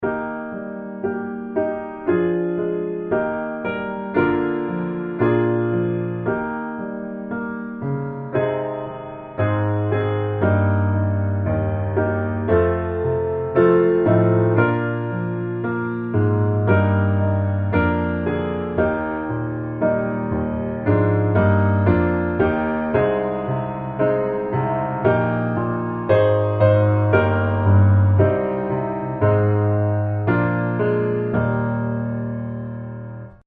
Eb Majeur